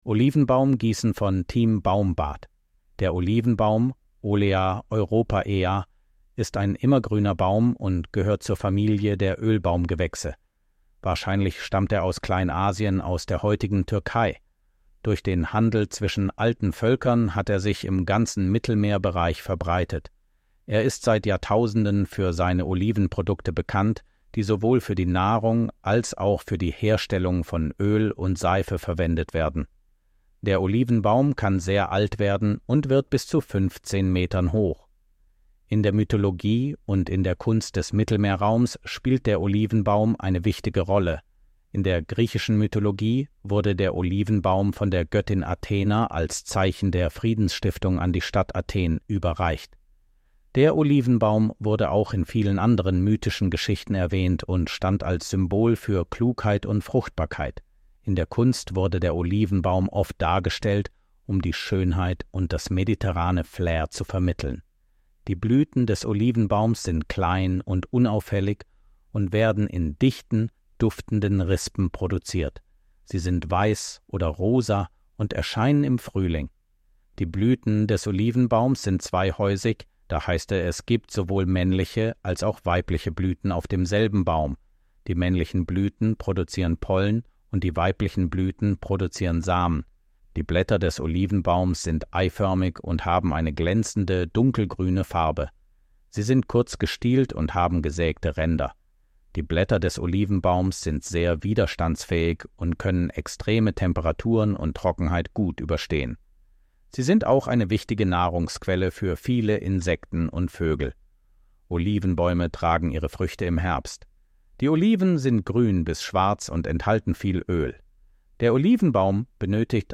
Artikel vorlesen